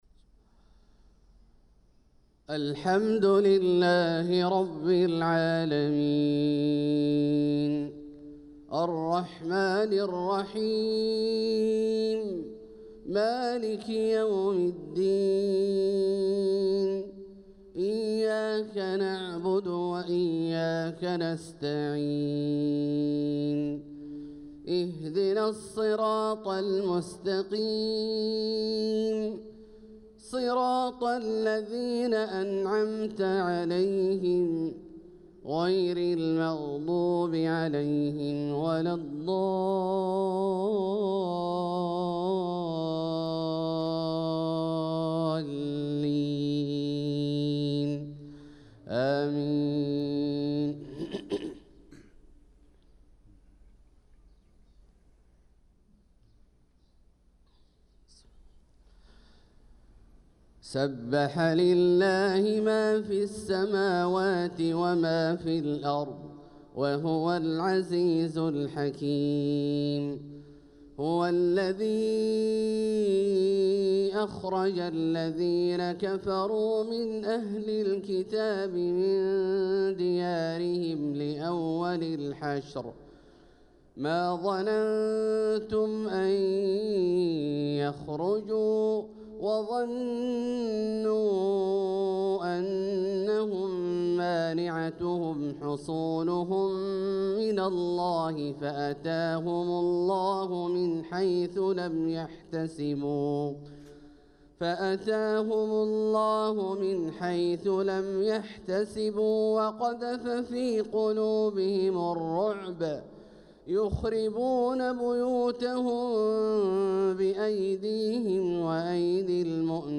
صلاة الفجر للقارئ عبدالله الجهني 24 صفر 1446 هـ
تِلَاوَات الْحَرَمَيْن .